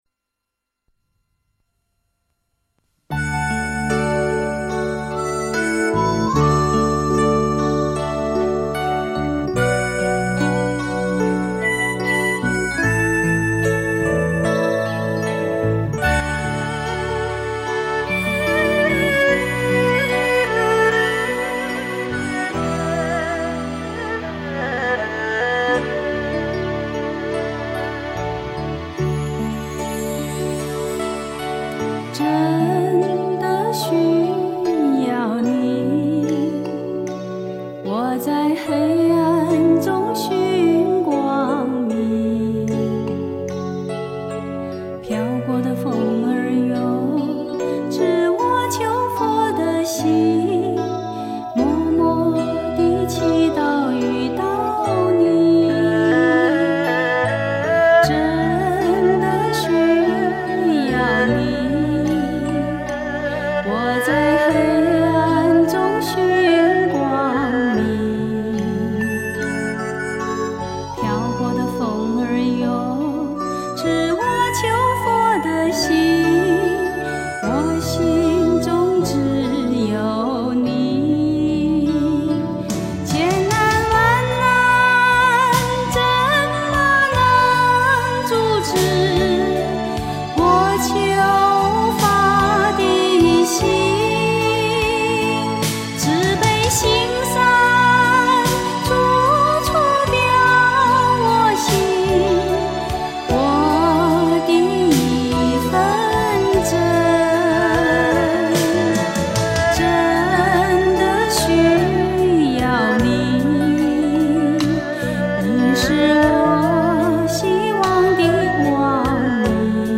佛音 诵经 佛教音乐 返回列表 上一篇： 捉真性 下一篇： 云水逸 相关文章 阿弥陀佛佛号(四字五音